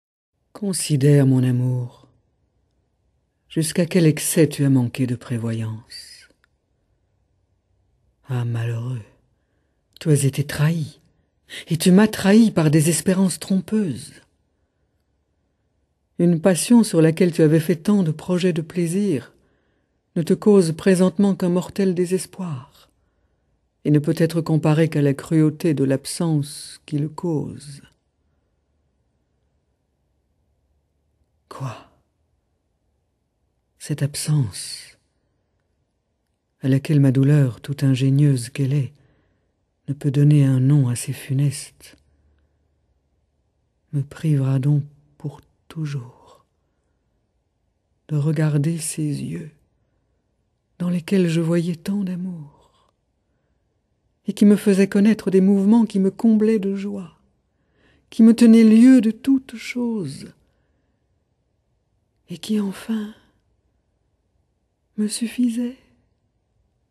Sa voix classique et chaude nous fait vivre avec émotions et intensité les affres de cette passion ardente !